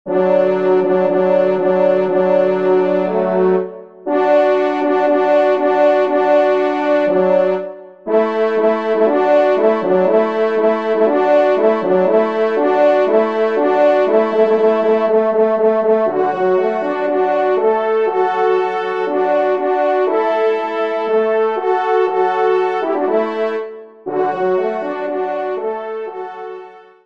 2ème Trompe